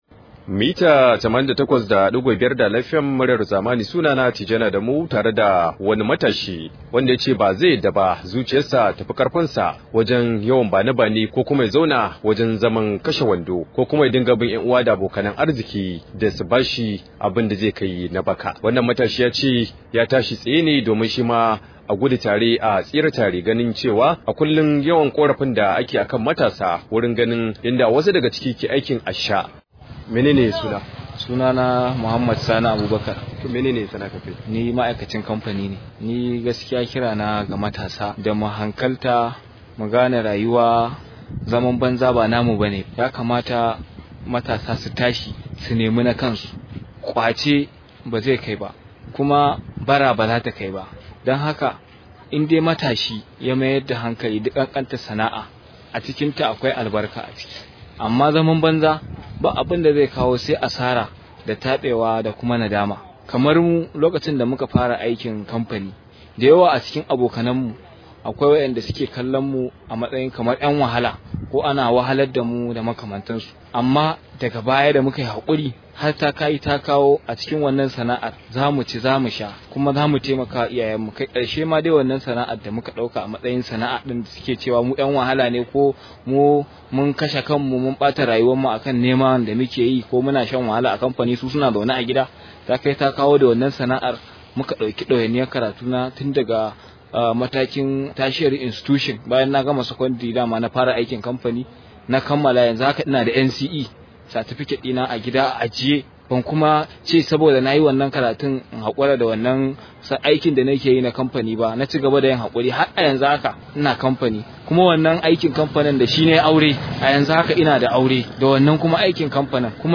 Rahoto: Da in zauna zuciya ta ta mutu gwanda na yi aikin karfi – Matashi